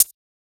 Index of /musicradar/ultimate-hihat-samples/Hits/ElectroHat B
UHH_ElectroHatB_Hit-15.wav